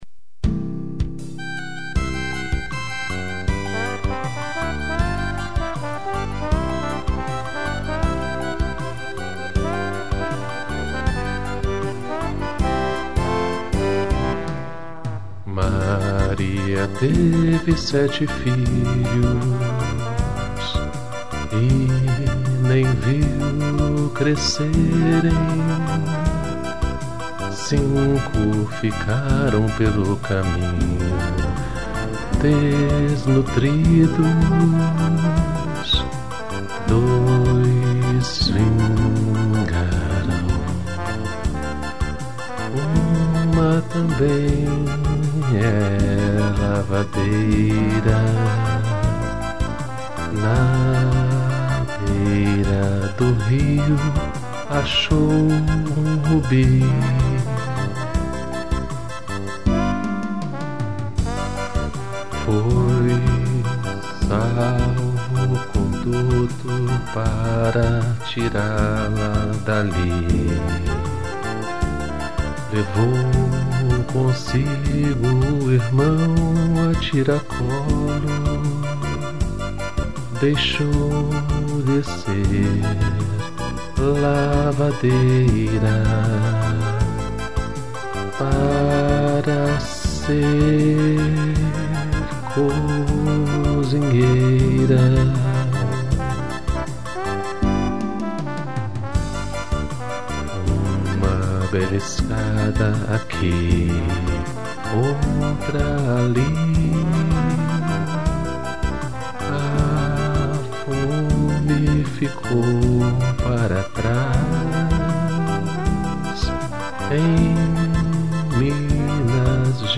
piano, violão e trombone